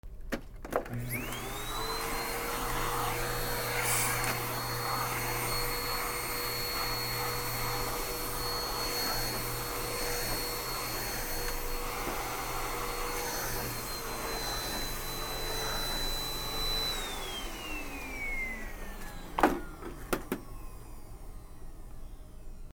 掃除機
『チャ キュゥィィーン』